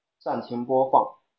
Nexdata/Chinese_Commands_Speech_Data_by_Bluetooth_Headset at main